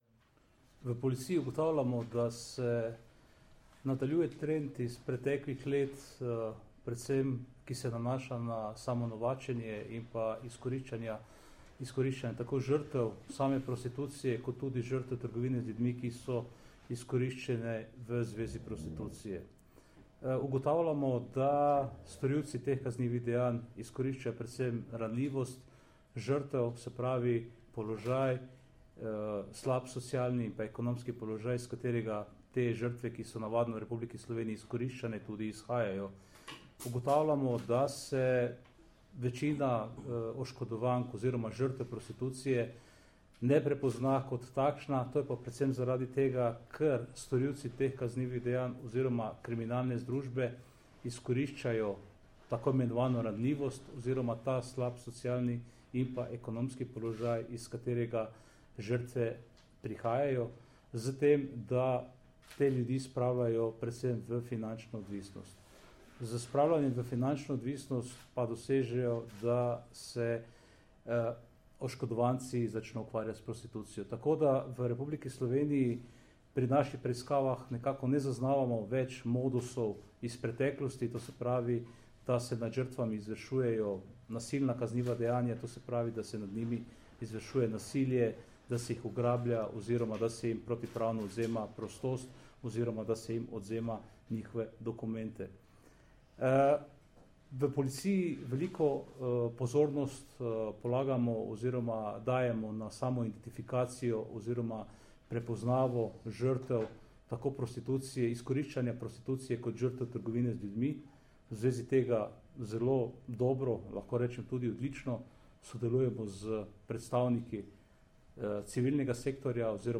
Zvočni posnetek izjave